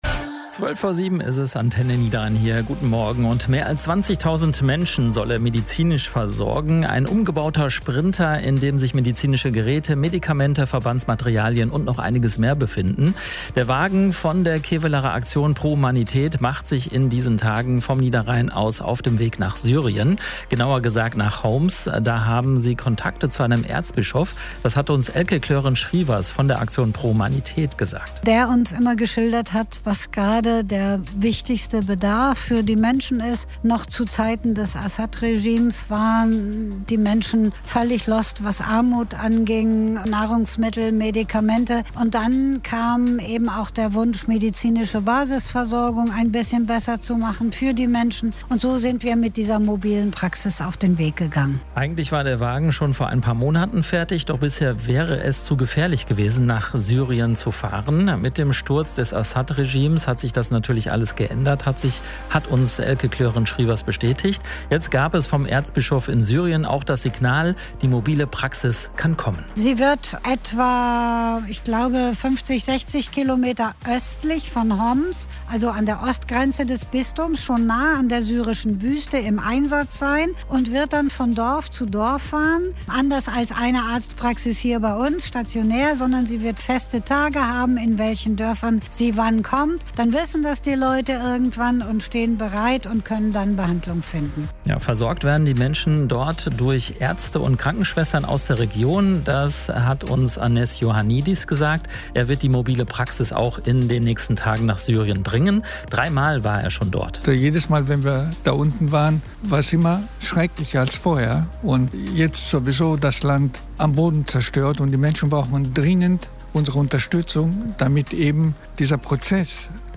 Radiobeitrag von Antenne Niederrhein